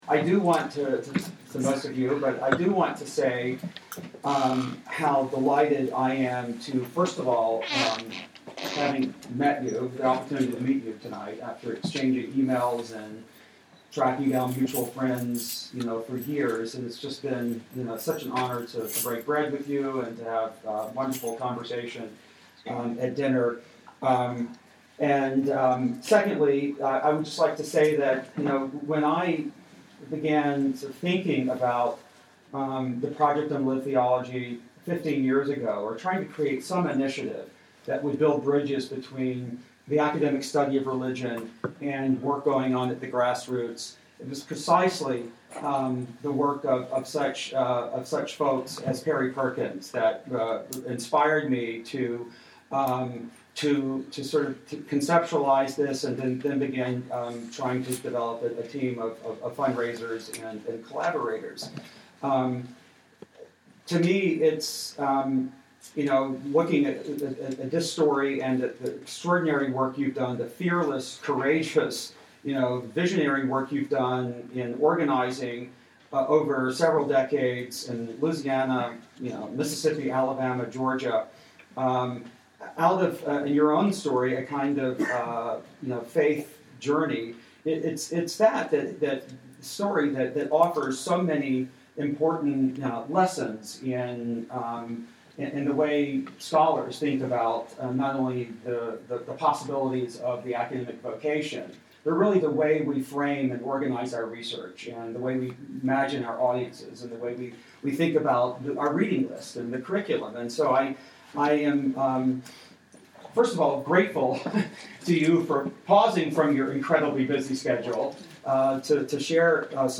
Lecture
Audio Information Date Recorded: November 8, 2012 Location Recorded: Charlottesville, VA Audio File: Download File » This audio is published by the Project on Lived Theology (PLT).